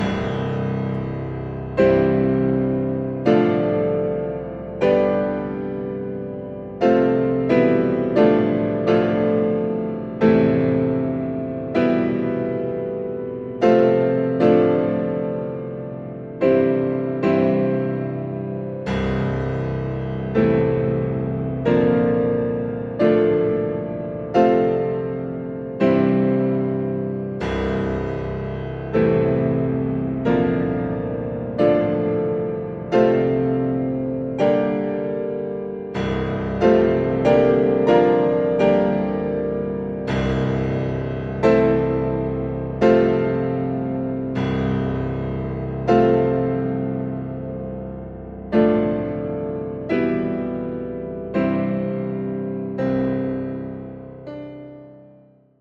zetting met parallellen (maat 28-40 van de 'cathedrale engloutie' uit Preludes, band 1, van Debussy)
Uitgevoerd door Nelson Freire.